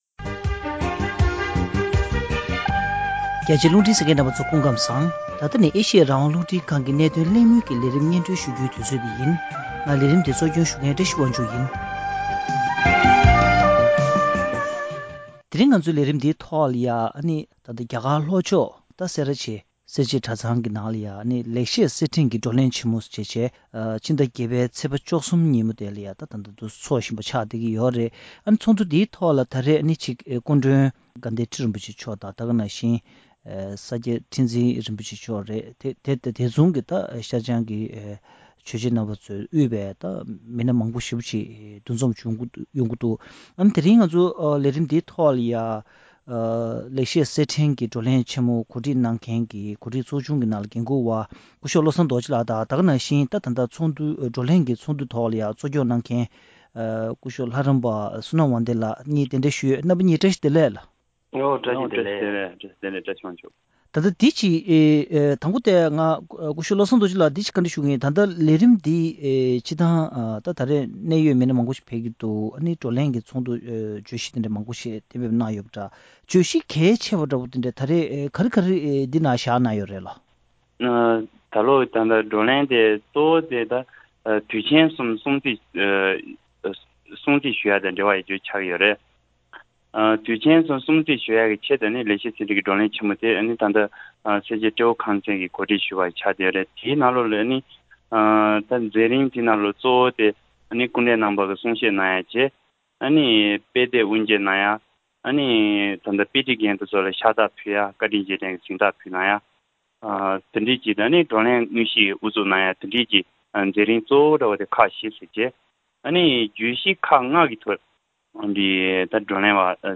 ༄༅།།གནད་དོན་གླེང་མོལ་གྱི་ལས་རིམ་ནང་།